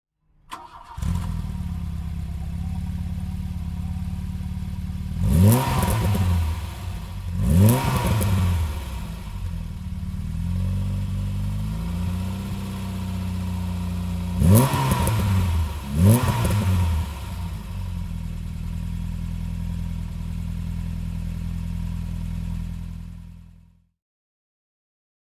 VW Golf GTI (1980) - Starten und Leerlauf
VW_Golf_GTI_1980.mp3